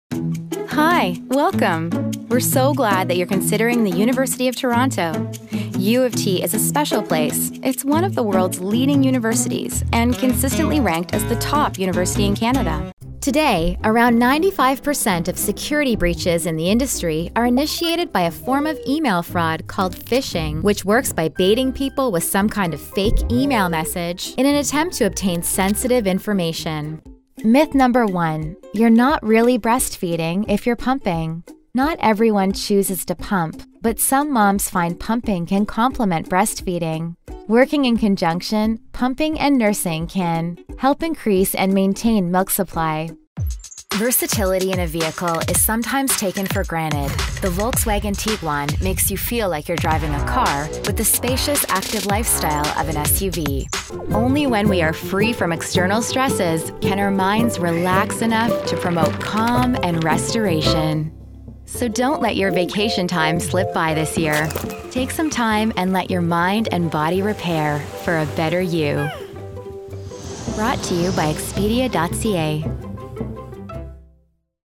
Female
I’m a Canadian voiceover artist with a native North American accent and a warm, youthful, relatable sound.
Dry Studio Read.mp3
Microphone: Rode NT1-A